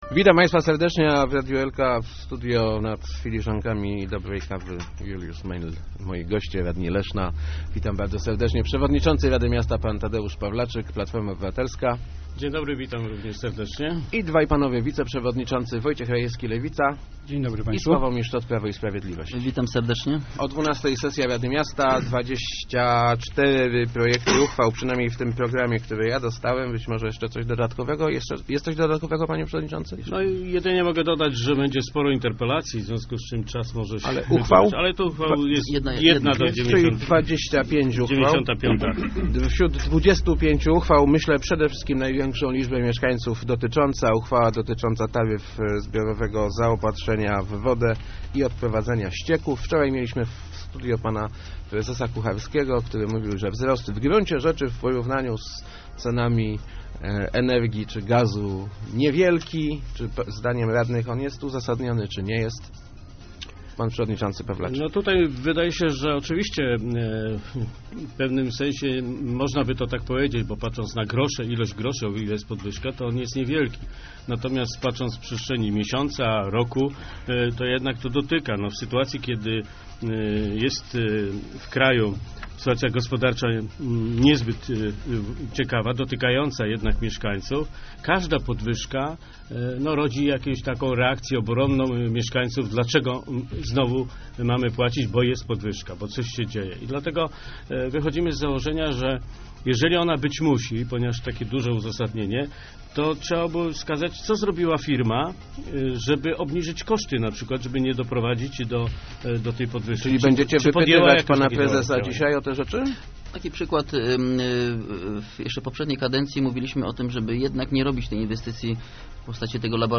Podwyżka opłat za wodę i ścieki nie znajduje uzasadnienia - mówili w Radiu Elka Tadeusz Pawlaczyk z PO i Sławomir Szczot z PiS. Oba kluby będą głosować przeciwko nowym, wyższym o ponad 4,5 procent taryfom.